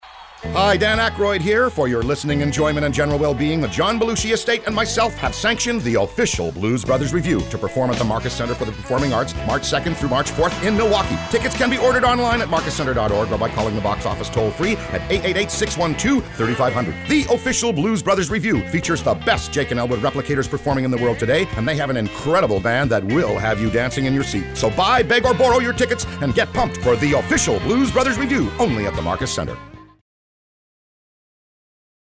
Blues Brothers Review Radio Commercial